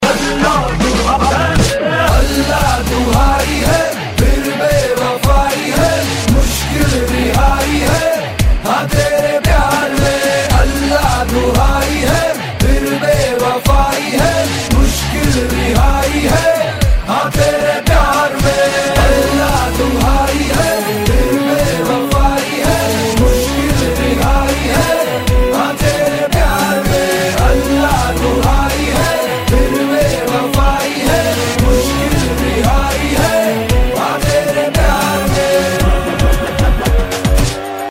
Мощный Индийский Рингтон